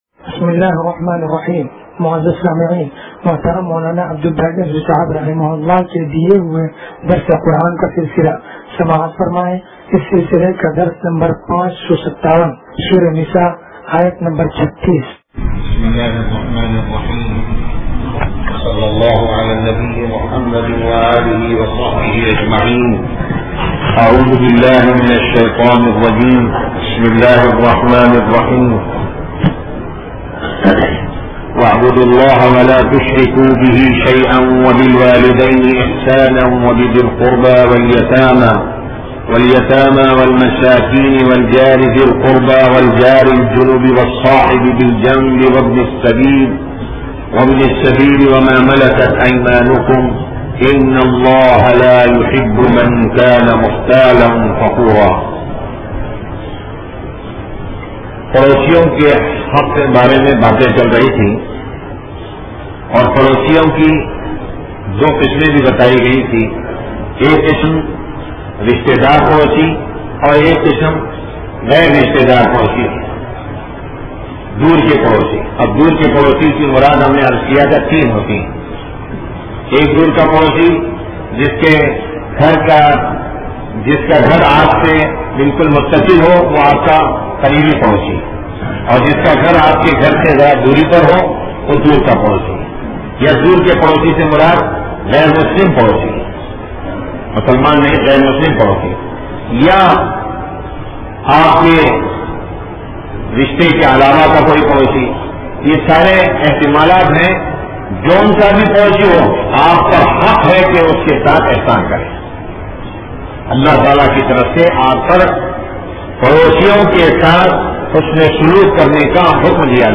درس قرآن نمبر 0557